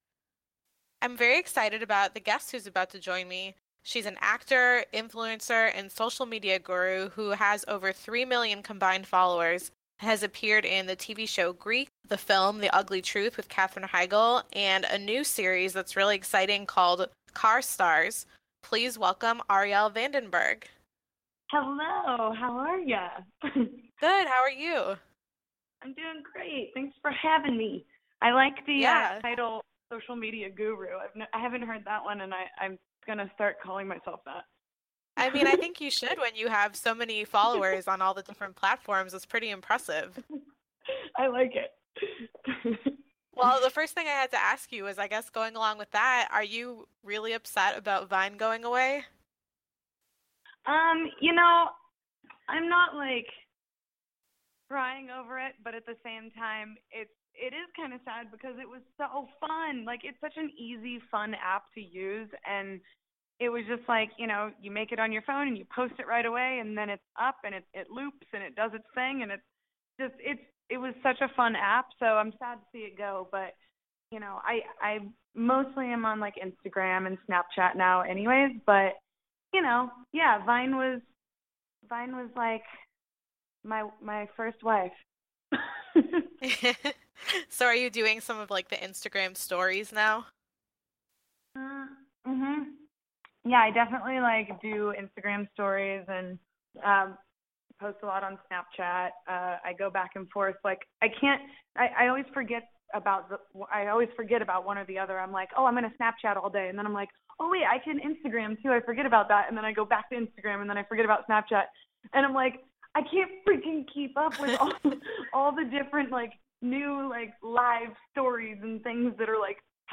Arielle Vandenberg - Interview